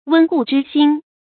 注音：ㄨㄣ ㄍㄨˋ ㄓㄧ ㄒㄧㄣ
溫故知新的讀法